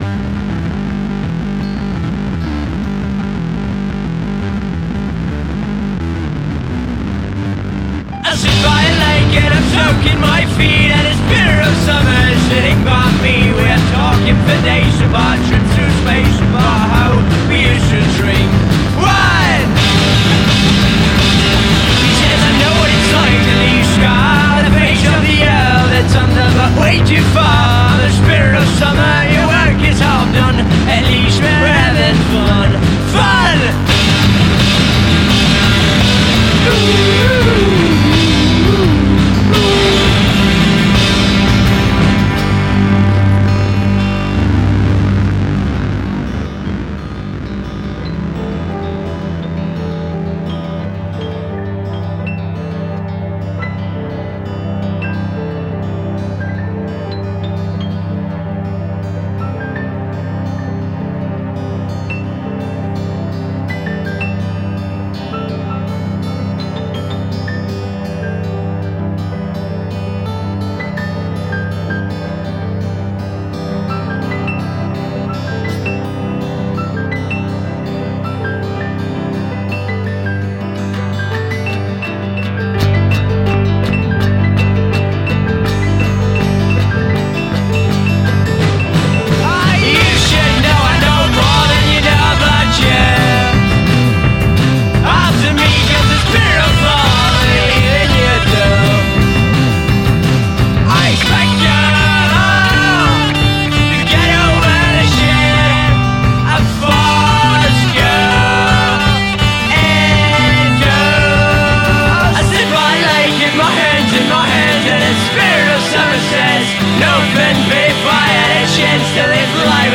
Žánr: Indie/Alternativa
guitar, piano, toy piano, beats, vocals
guitars, piano, strings, synths
bass, synths, samples, beats, electronics